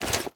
Minecraft Version Minecraft Version 25w18a Latest Release | Latest Snapshot 25w18a / assets / minecraft / sounds / item / armor / equip_gold1.ogg Compare With Compare With Latest Release | Latest Snapshot
equip_gold1.ogg